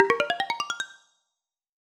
Prize Wheel Count (1).wav